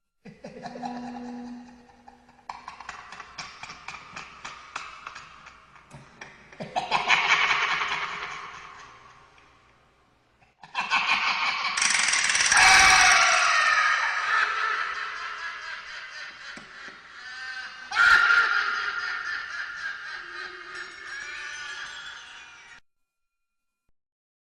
Звуки со смехом злого ученого, маньяка и его лаборатория для монтажа видео в mp3 формате.
4. Смех ученого в пустом помещении